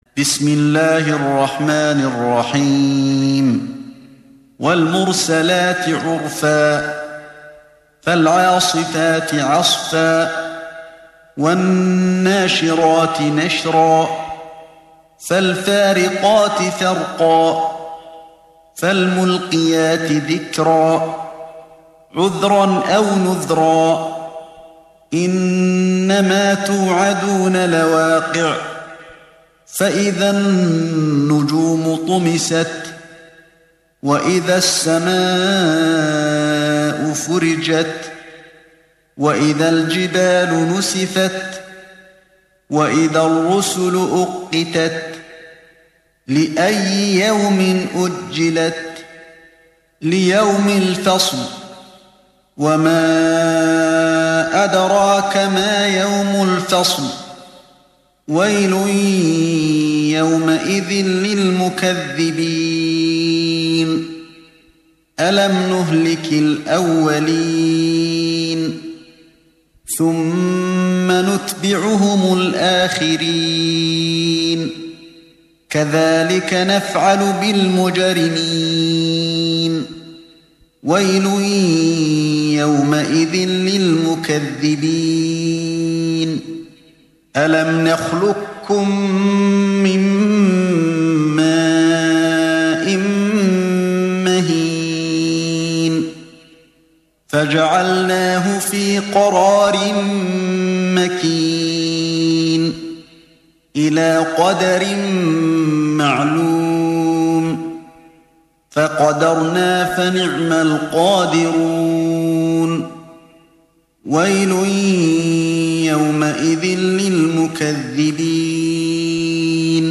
دانلود سوره المرسلات mp3 علي الحذيفي روایت حفص از عاصم, قرآن را دانلود کنید و گوش کن mp3 ، لینک مستقیم کامل